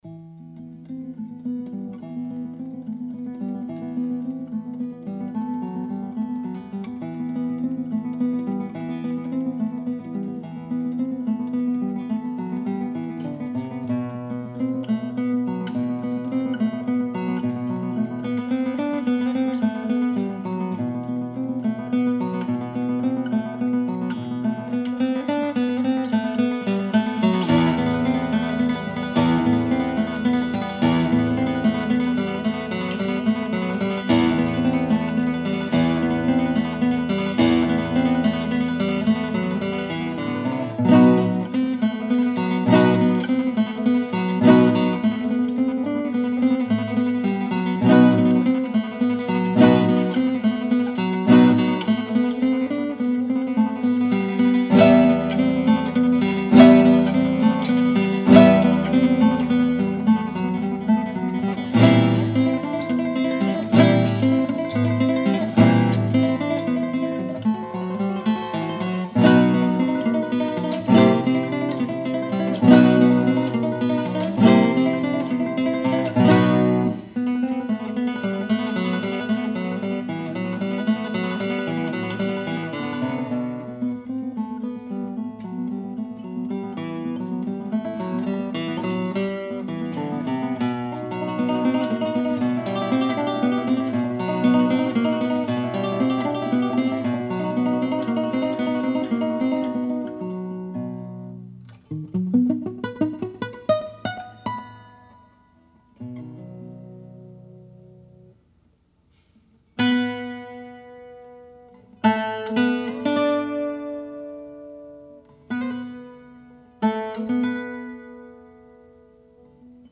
This was originally written for piano but it gained more popularity as a guitar transcription and became a must for every guitarist's repertoire.